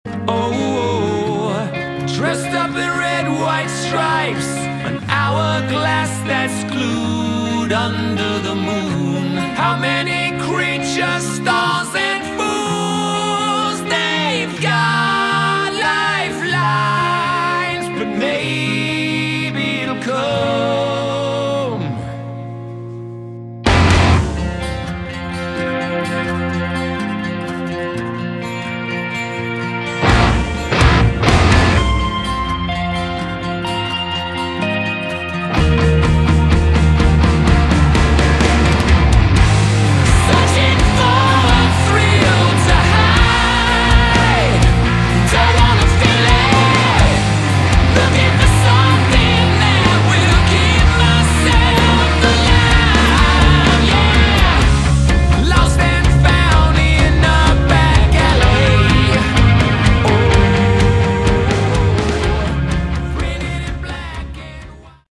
Category: Hard Rock
lead vocals, bass
guitar
drums